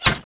knife_slash2.wav